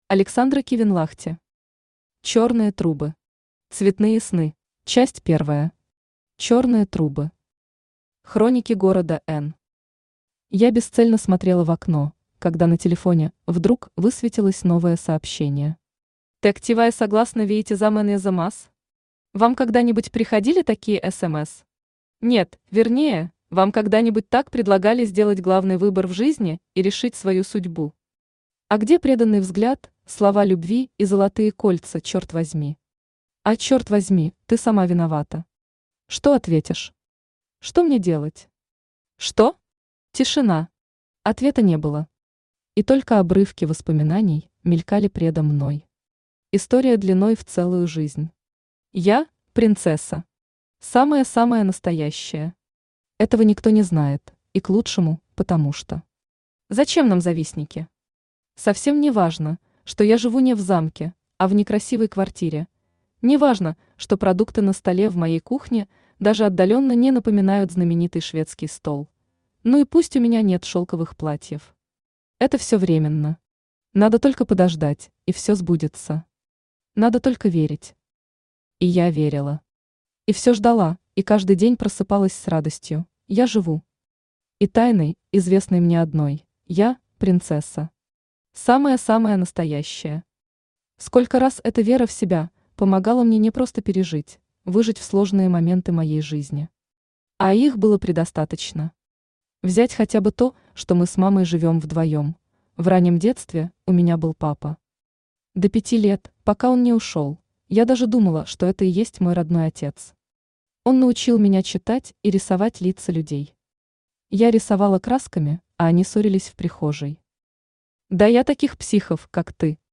Аудиокнига Черные трубы. Цветные сны | Библиотека аудиокниг
Цветные сны Автор Александра Кивенлахти Читает аудиокнигу Авточтец ЛитРес.